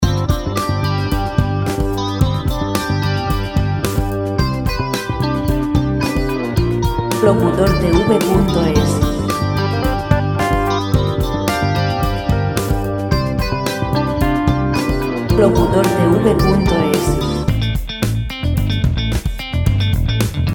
Música  pop libre de derechos de autor.